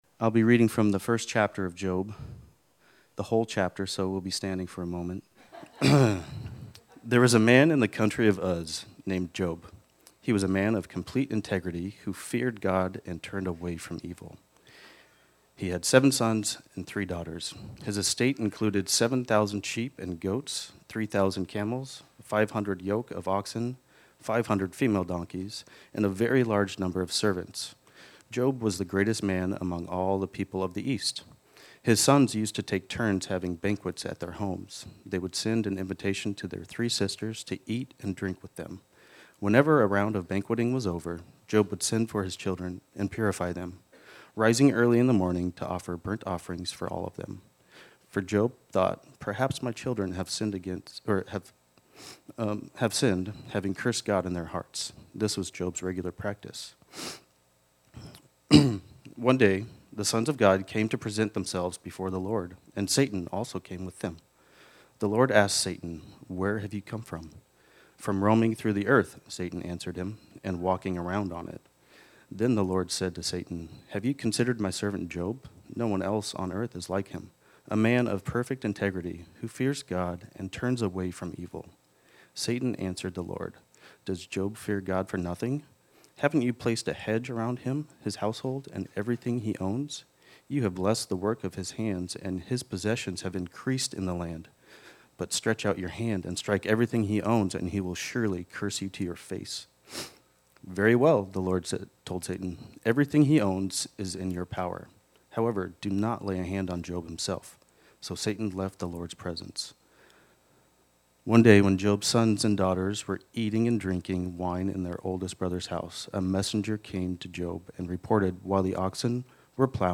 This sermon was originally preached on Sunday, January 4, 2025.